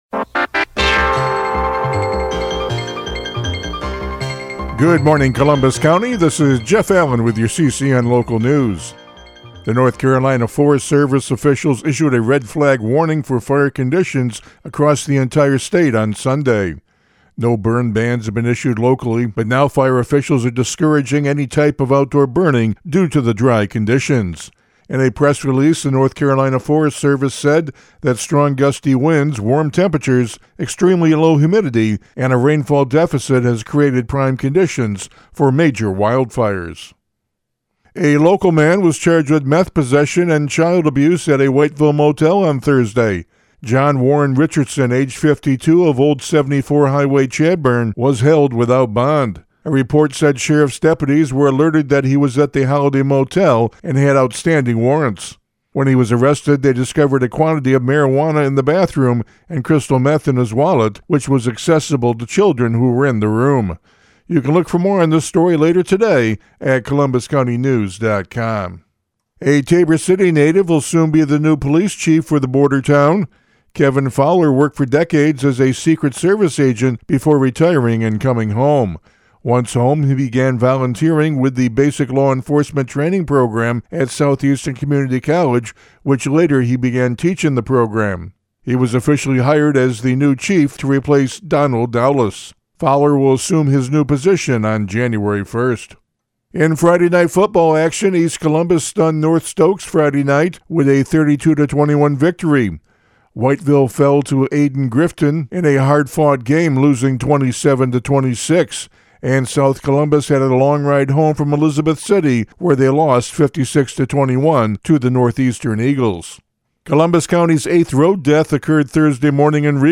CCN Radio News — Morning Report for November 17, 2025